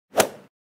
sound-effect-golf-hit.mp3